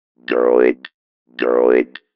Having listened to the Motorola “Droiod” it’s a bit like the voice synthesis on Speak n Spell (ask your parents :slight_smile: ) …